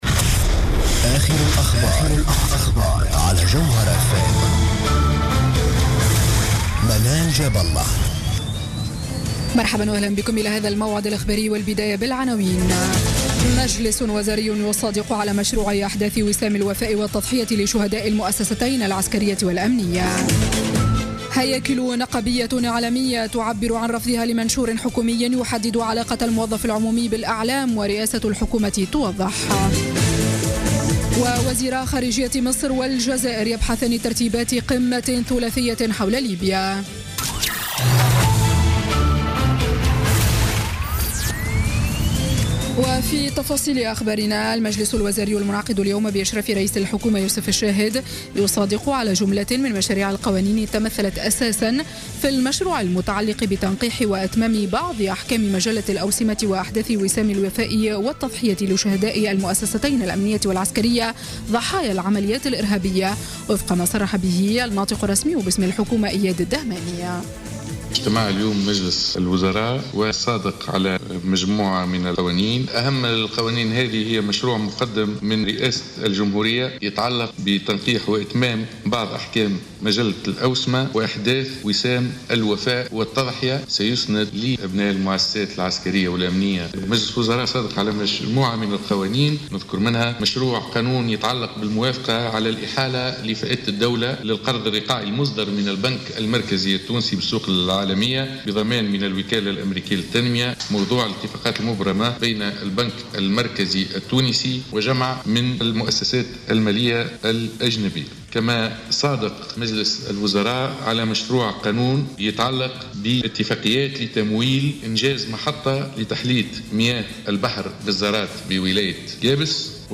نشرة أخبار السابعة مساء ليوم الجمعة 27 جانفي 2017